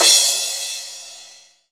CYM CRA05.wav